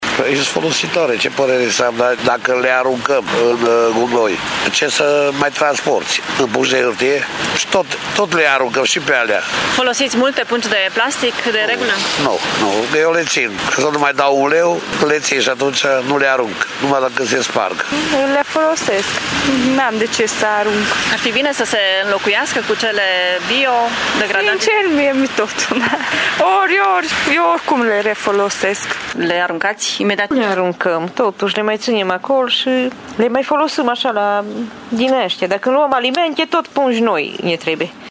Târgumureșenii spun că reutilizează des pungile mai rezistente pe care le cumpără și nu cred că se pot înlocui în totalitate cele de polietilenă: